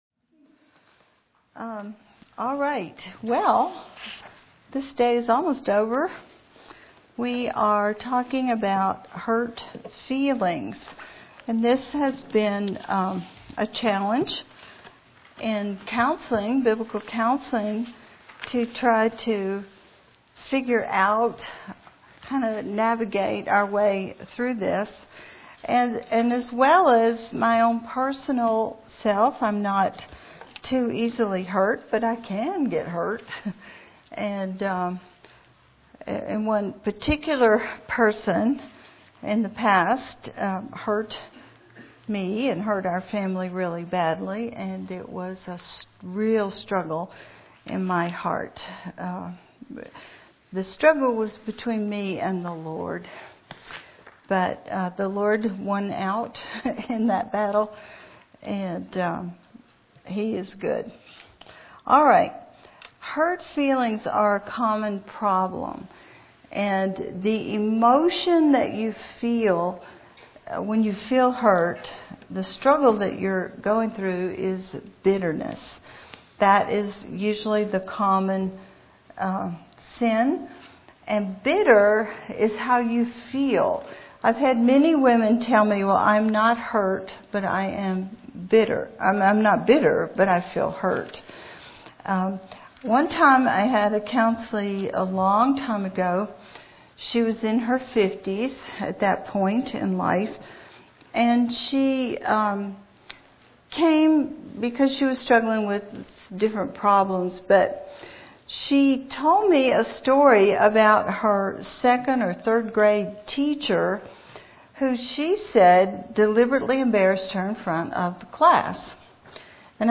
Cornerstone Church and Teton Valley Bible Church Women’s Conference 2011: “Unfading Beauty”